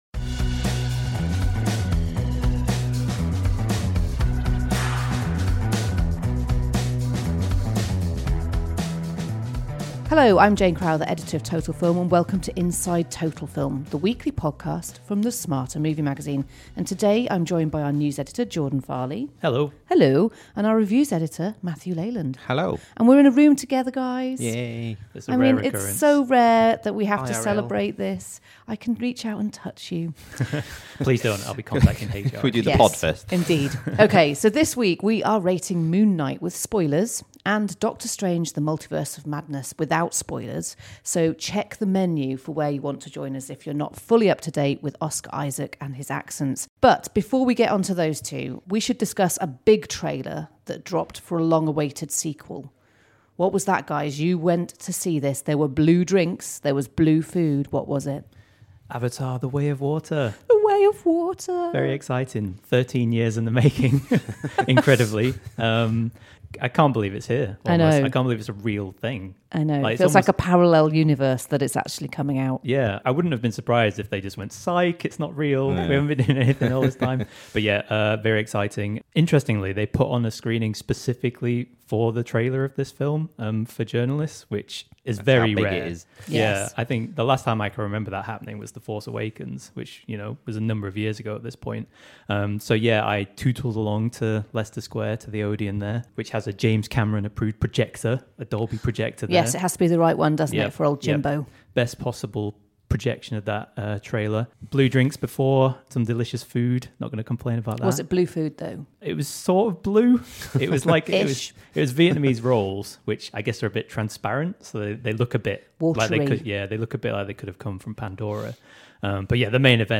Plus we discuss best movie gardens and there's a dreadful Oscar Isaac impression.